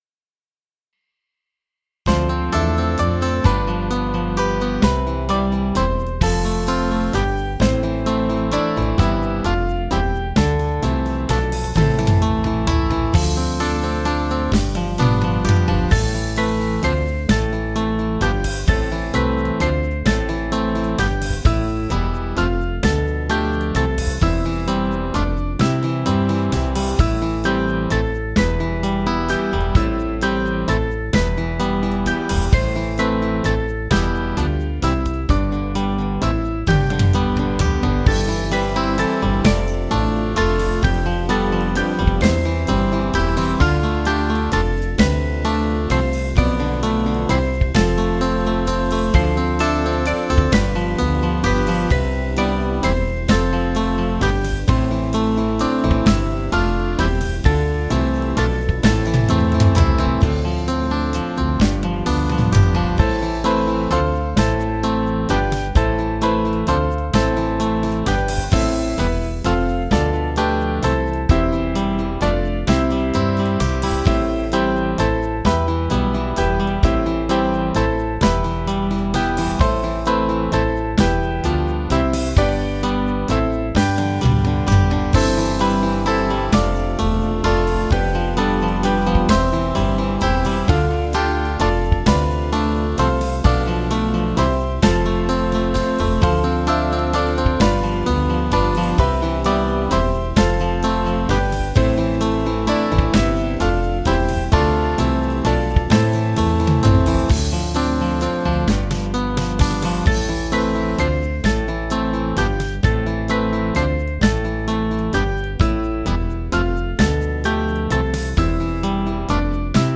Small Band
(CM)   4/Am